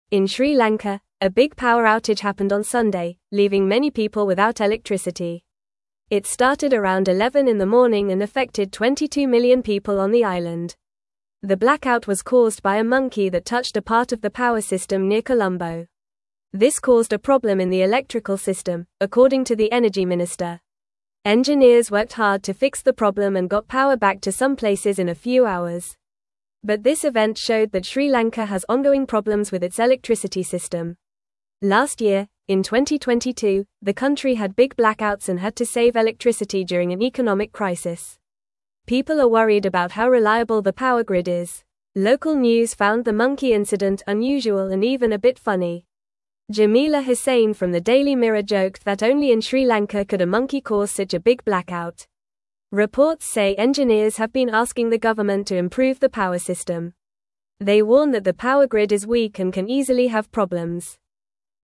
Fast
English-Newsroom-Lower-Intermediate-FAST-Reading-Monkey-Turns-Off-Lights-in-Sri-Lanka-for-Everyone.mp3